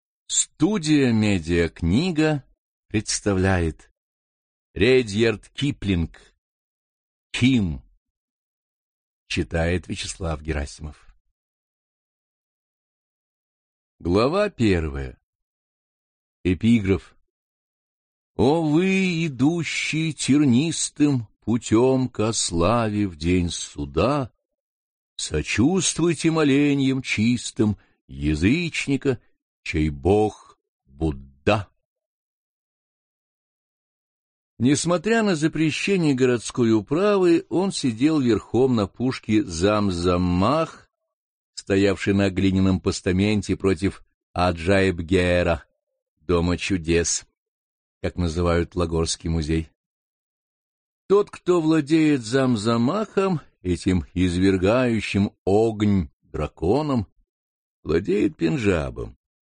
Аудиокнига КИМ | Библиотека аудиокниг